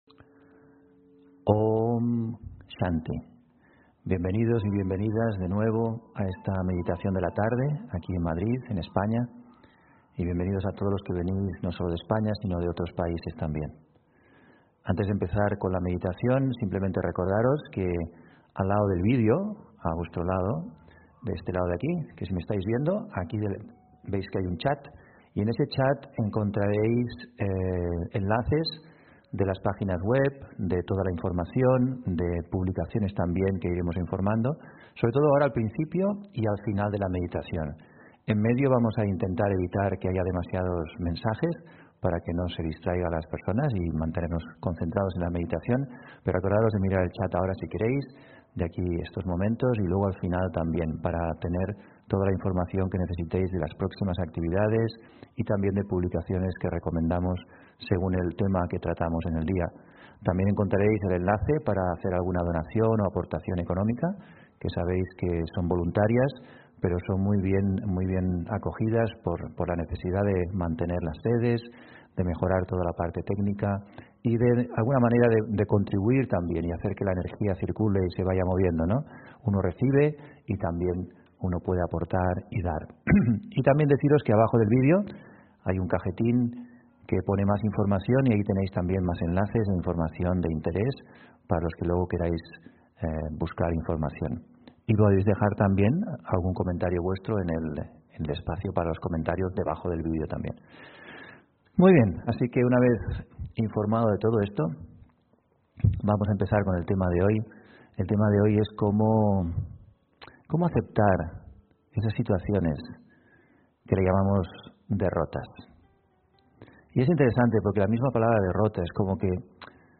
Audio conferencias
Meditación Raja Yoga: Aceptar las derrotas (30 Octubre 2020) On-line desde Madrid